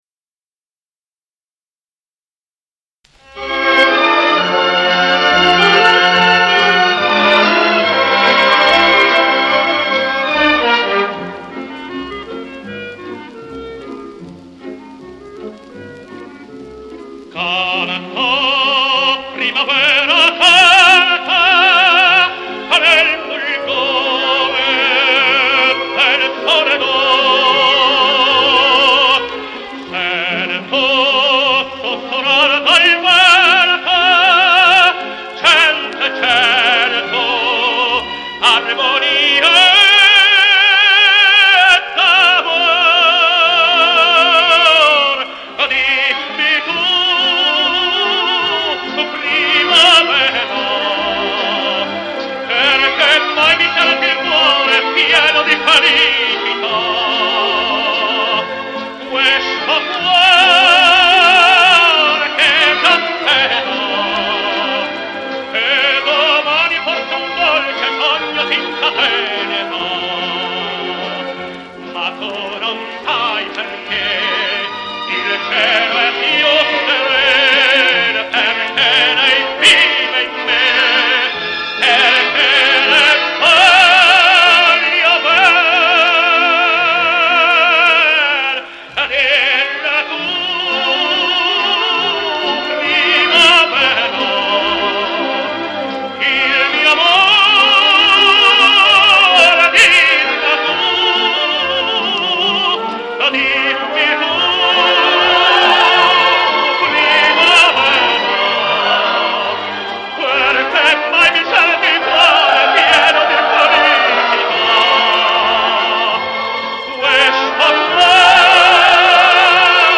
con accompagnamento d'orchestra